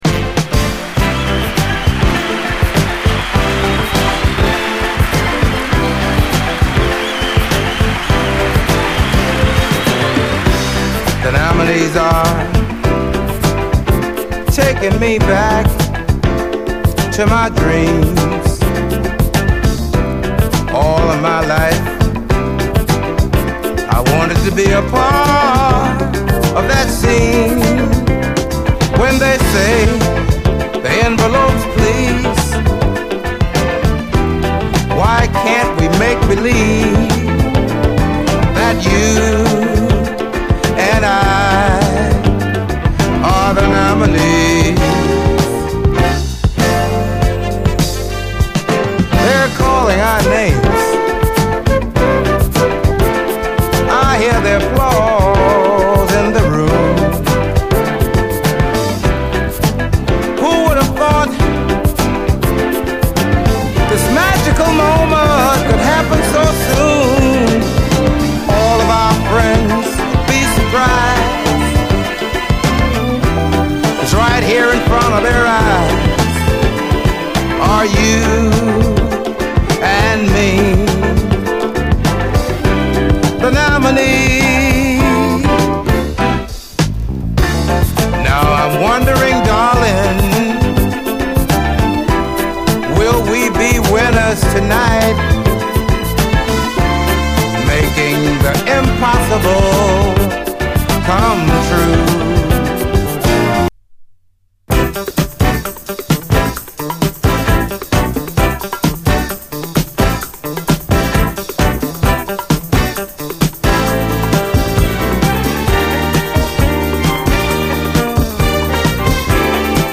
SOUL, 70's～ SOUL, DISCO
最高モダン・ソウル盤！